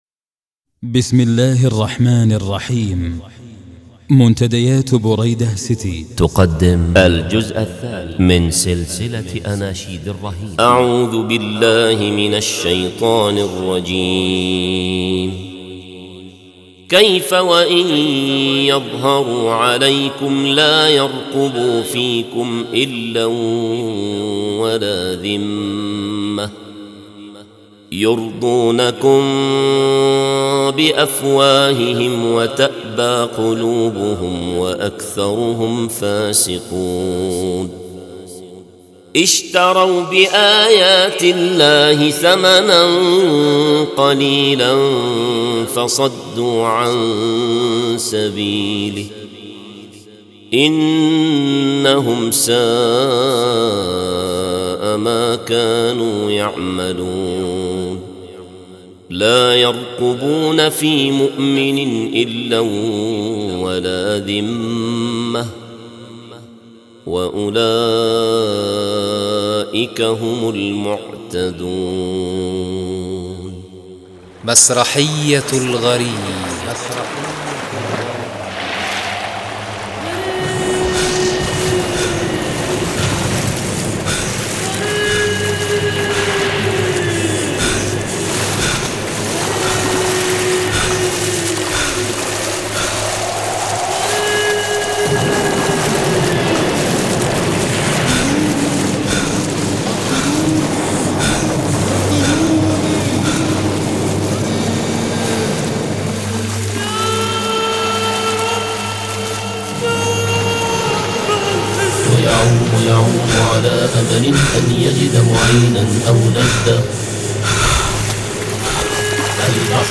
ذي النشيييده .. :